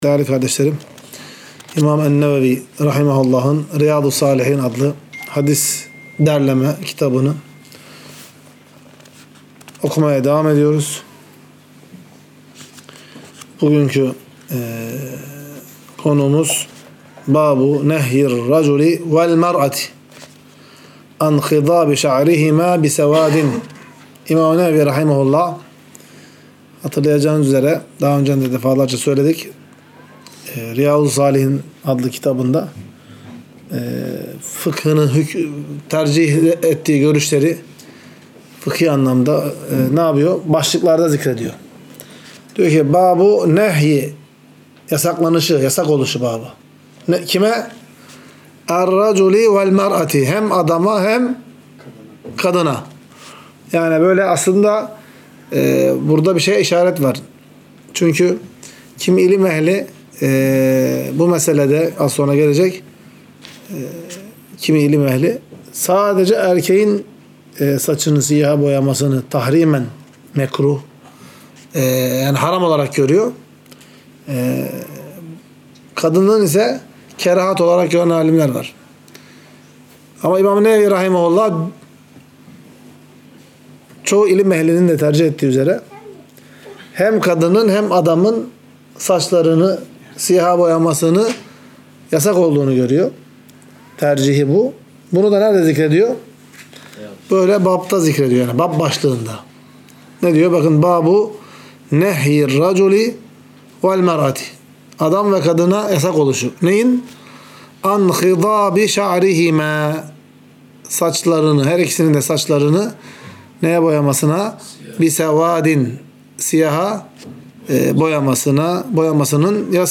Ders - 41. Bölüm | Erkeklerin ve Kadınların Saçlarını Siyaha Boyamalarının Yasak Olduğu